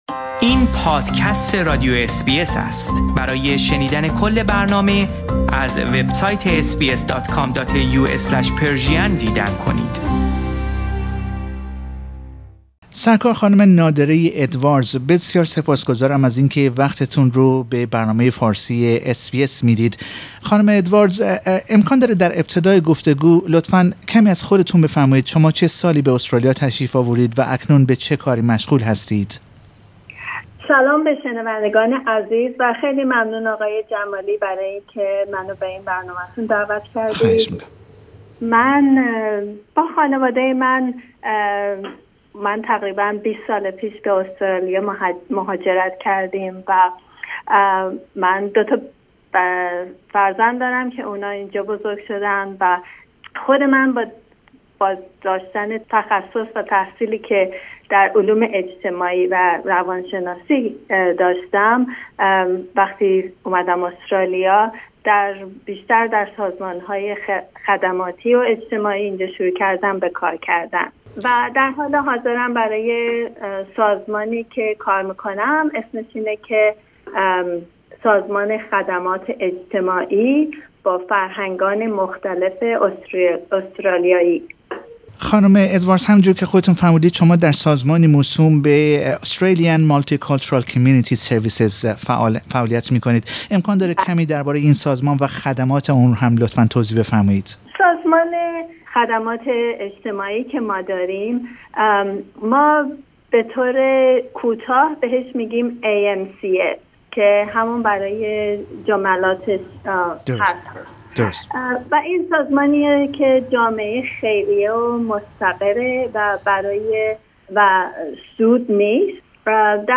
در گفتگو با رادیو اس بی اس فارسی